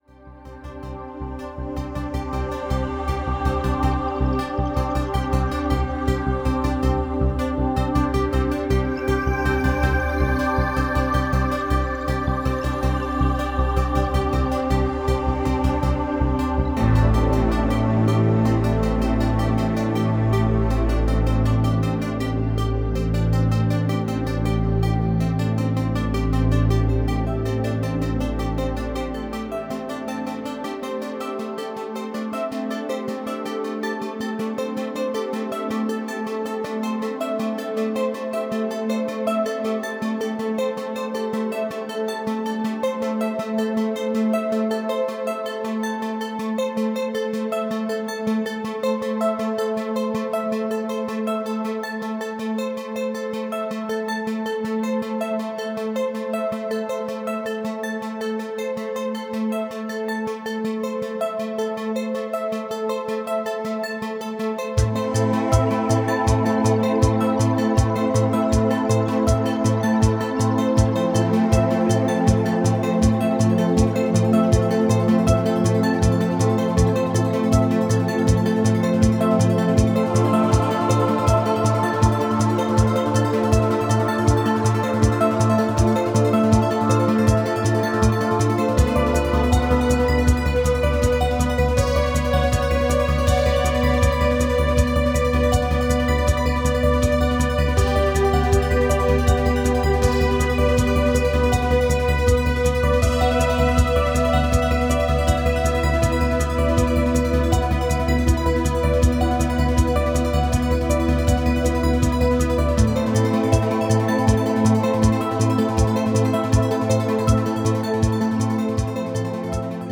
harmonic
spacey
dreamy
sequencer-based
relaxing
Music to dream to.